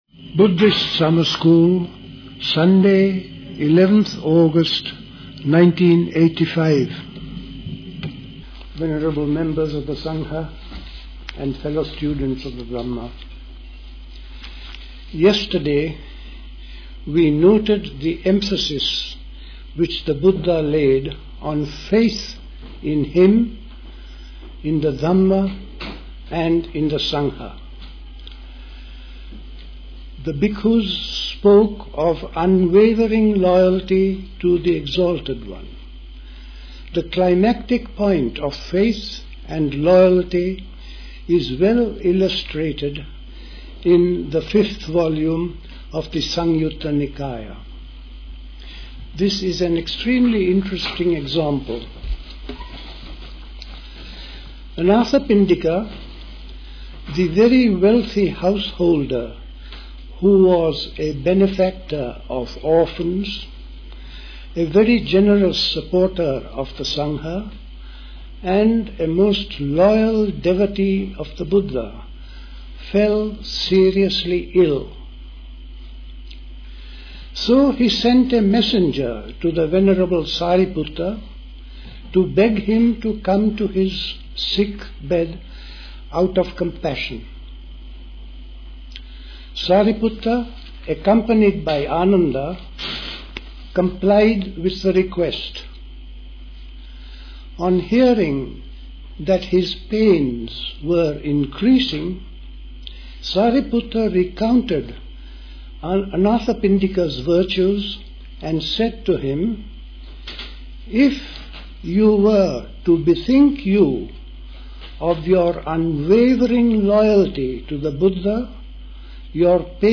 A talk
at High Leigh Conference Centre, Hoddesdon, Hertfordshire
The Buddhist Society Summer School Talks